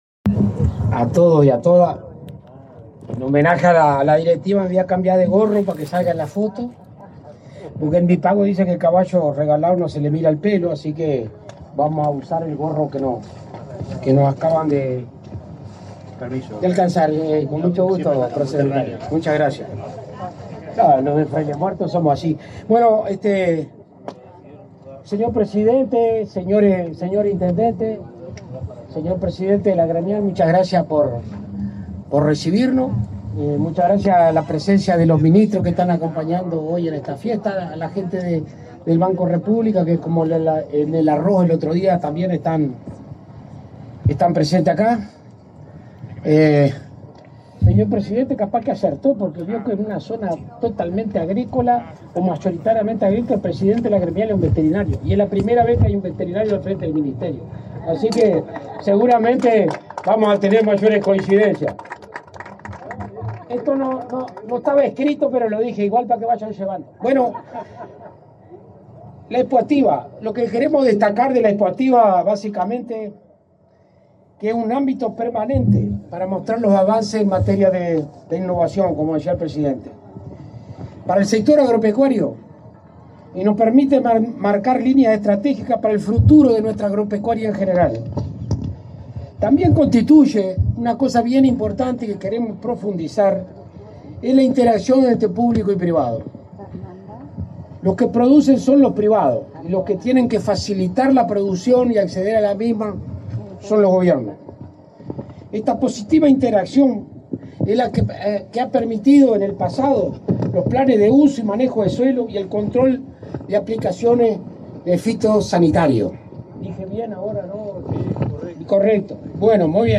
Palabras del ministro de Ganadería, Agricultura y Pesca, Alfredo Fratti
Palabras del ministro de Ganadería, Agricultura y Pesca, Alfredo Fratti 19/03/2025 Compartir Facebook X Copiar enlace WhatsApp LinkedIn El presidente de la República, profesor Yamandú Orsi, participó, este 19 de marzo, en la inauguración de la 28.° Expoactiva, en el departamento de Soriano. En el evento disertó el ministro de Ganadería, Agricultura y Pesca, Alfredo Fratti.